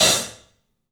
Index of /90_sSampleCDs/E-MU Producer Series Vol. 5 – 3-D Audio Collection/3DPercussives/3DPAHat